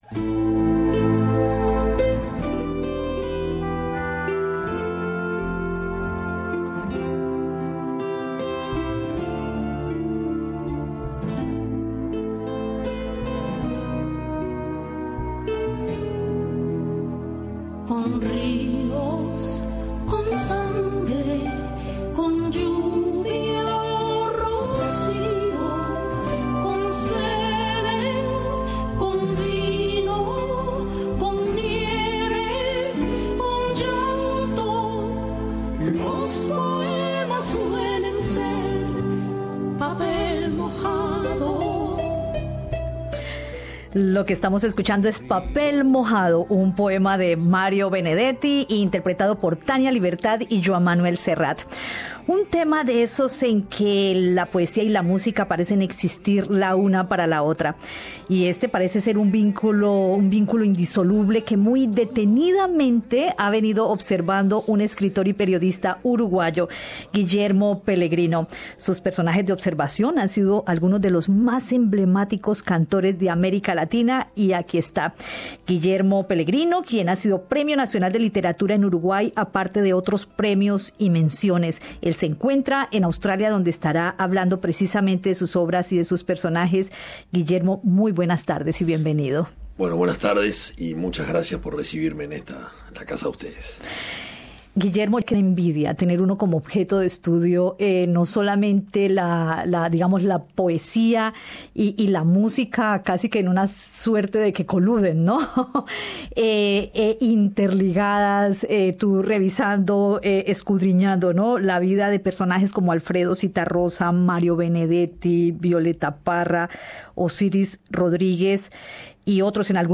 en los estudios de Radio SBS